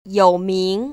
[yŏu//míng] 요우밍  ▶